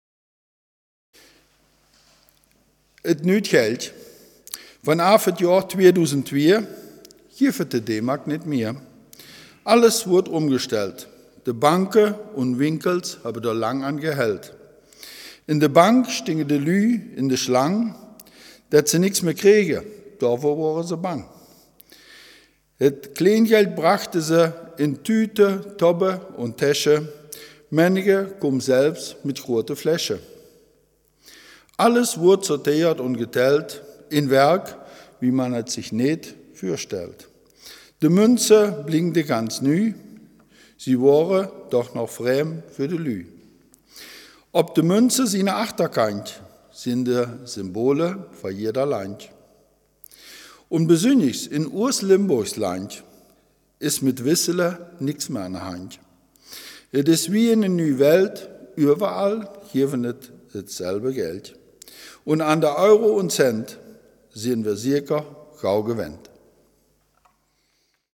Selfkant-Platt